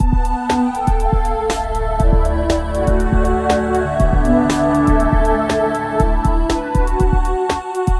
ambientmood_0006.wav